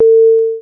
Level-Up Sound FX
app arp game games jackpot level-up mmorpg rpg sound effect free sound royalty free Memes